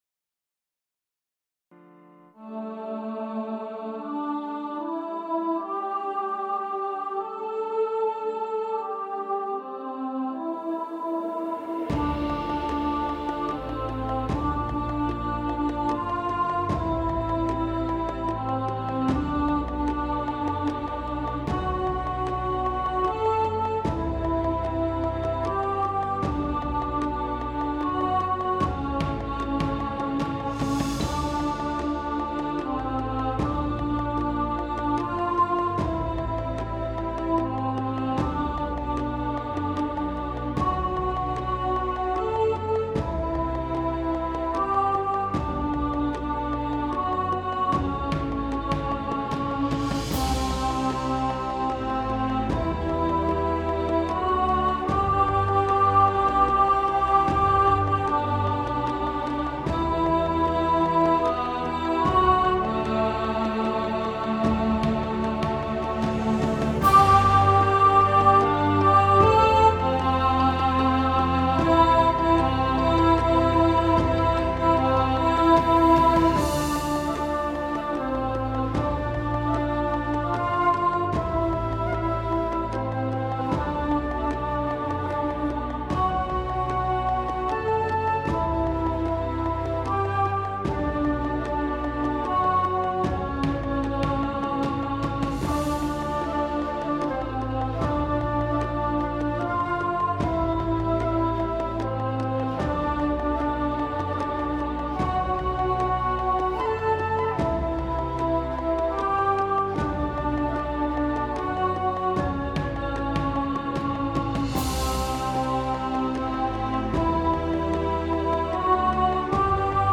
Adiemus – Alto | Ipswich Hospital Community Choir
Adiemus-Alto.mp3